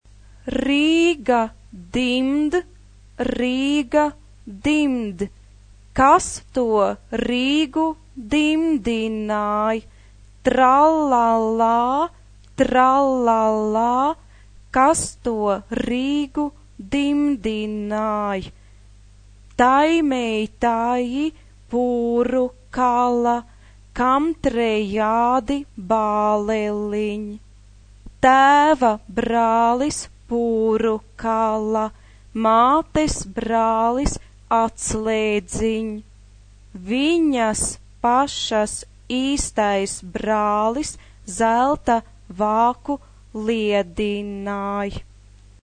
SATB (4 voices mixed) ; Full score.
Folk music. Partsong.
Mood of the piece: energetic Type of Choir: SATB (4 mixed voices )
Tonality: A major